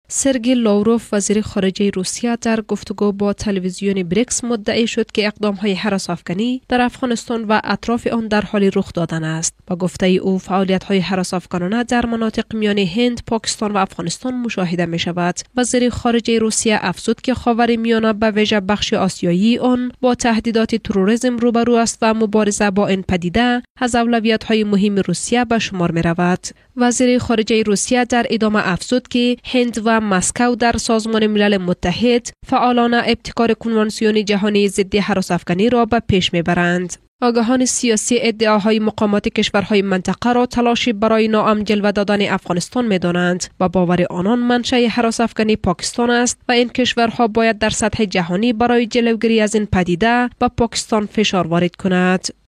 Voice Over Artistes- DARI
Leading Female Voice over in DARI language.